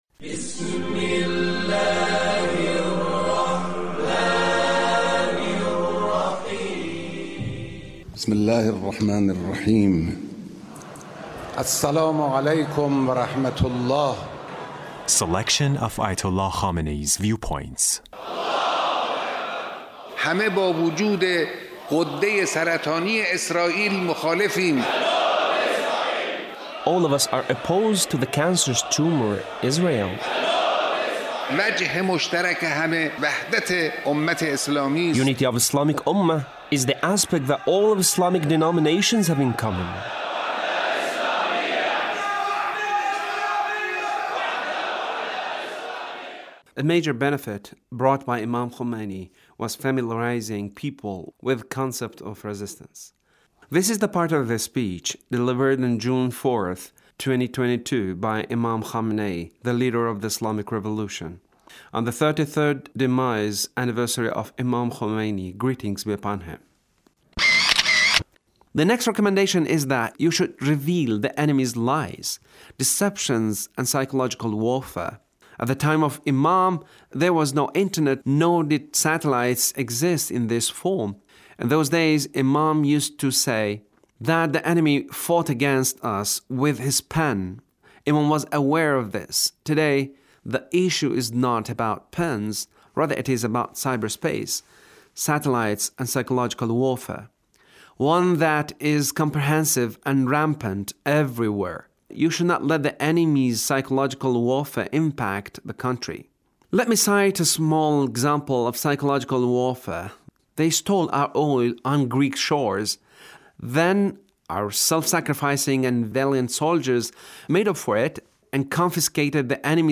The Leader's speech on The Demise Anniversary of The Imam